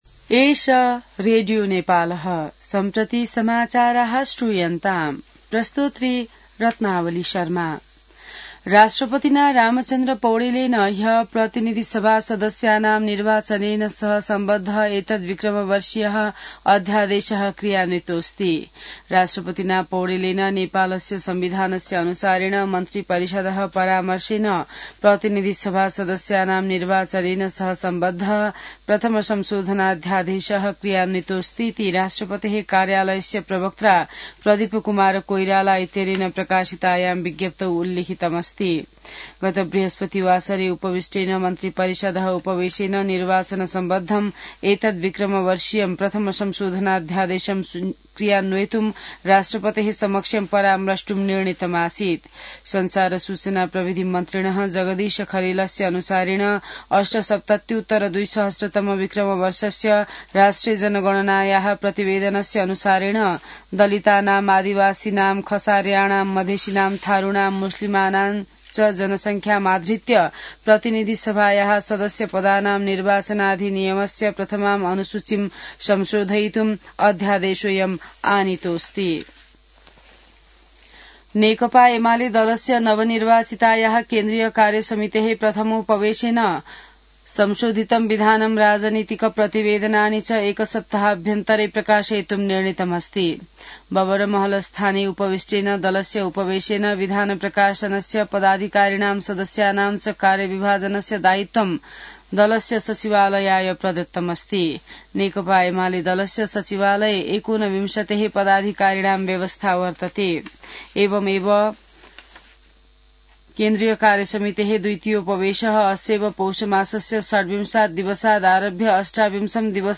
संस्कृत समाचार : ५ पुष , २०८२